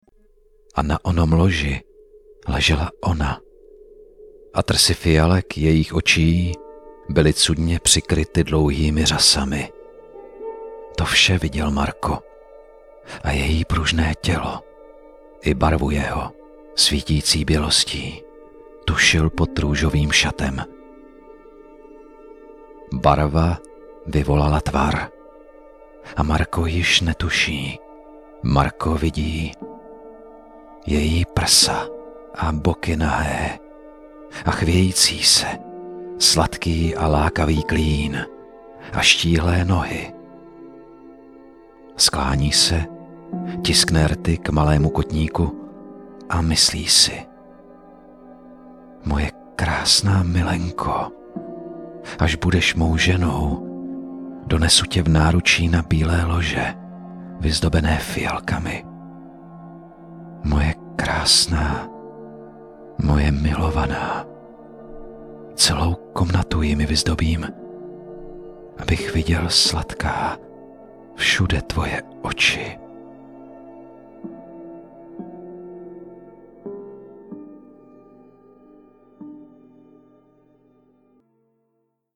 Umím: Voiceover
Vzhledem k sedmileté praxi v divadelní činohře jsem schopen s hlasem pracovat a přizpůsobit jej Vašim přáním a požadavkům.